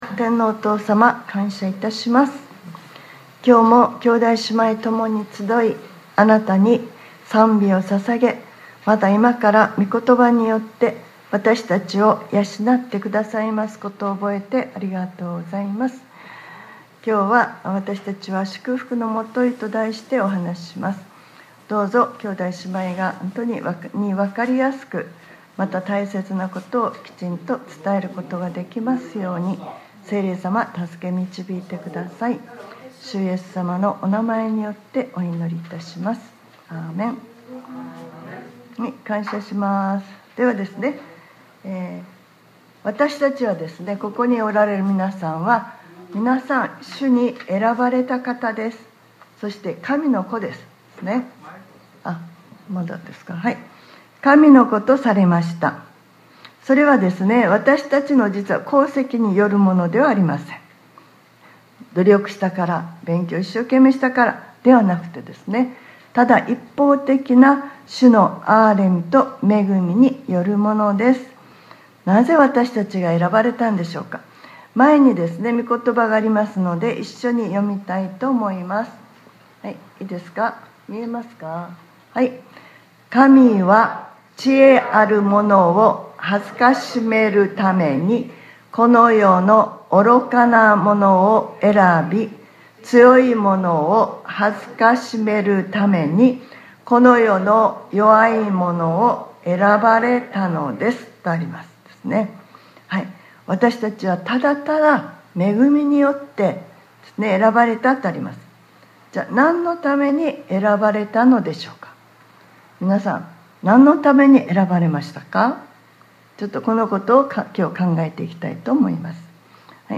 2025年01月19日（日）礼拝説教『 祝福の基 』